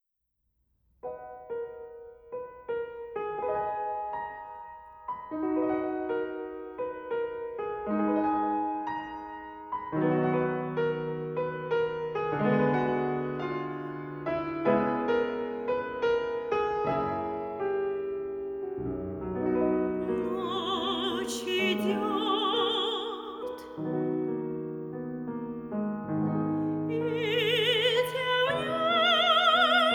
aufgenommen Herbst 2021 im Tonstudio